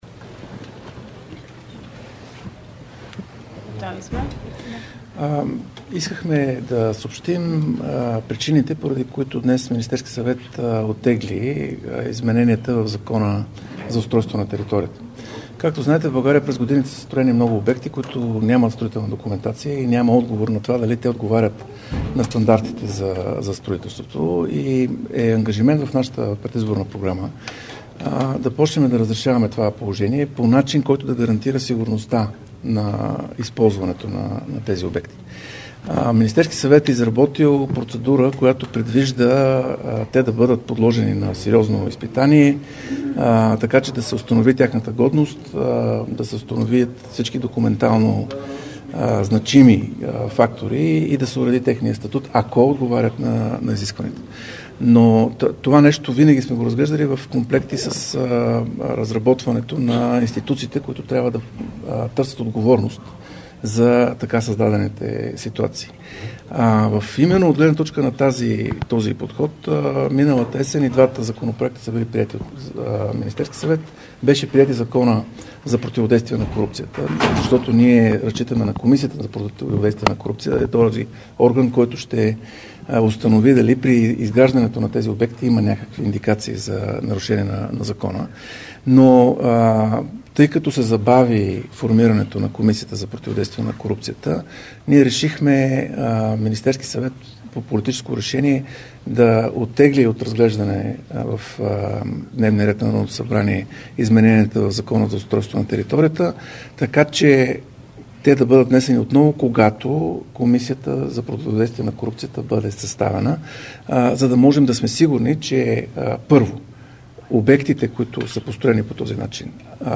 10.45 - Брифинг на министър-председателя Николай Денков, вицепремиера и външен министър Мария Габриел и министъра на външните работи на Великобритания Дейвид Камерън.- директно от мястото на събитието (Министерски съвет)
Директно от мястото на събитието